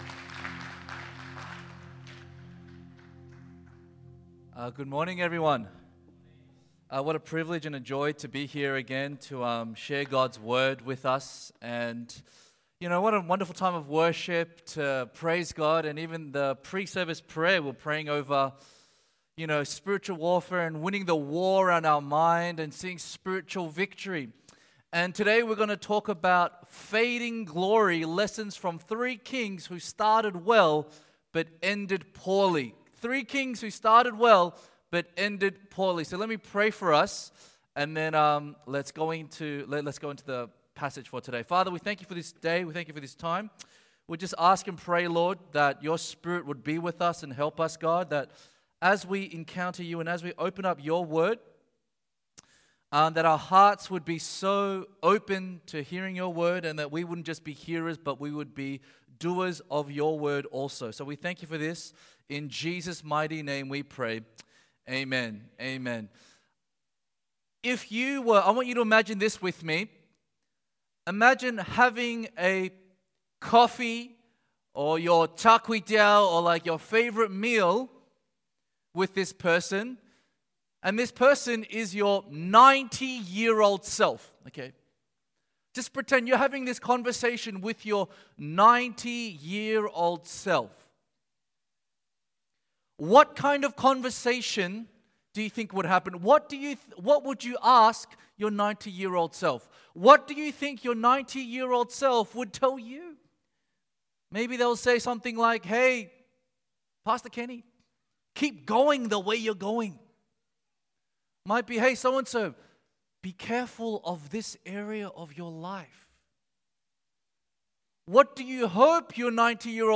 English Sermons | Casey Life International Church (CLIC)
English Worship Service 27th August 2023